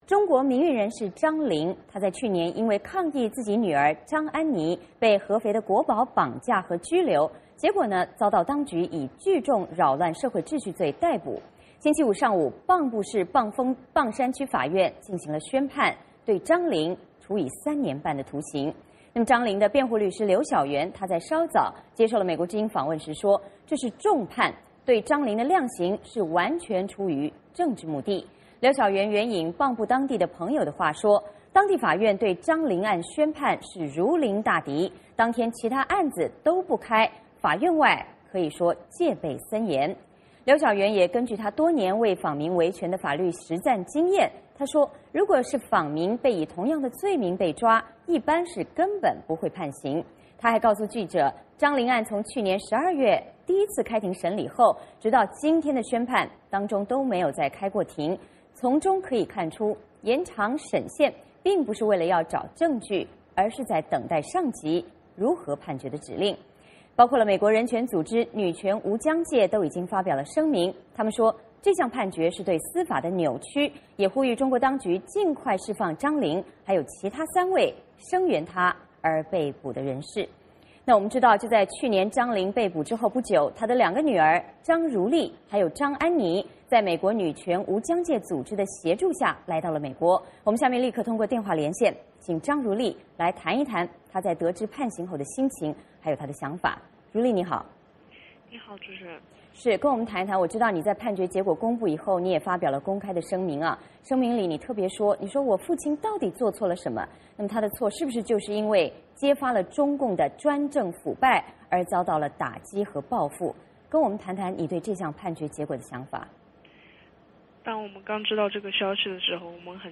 接下来我们通过电话连线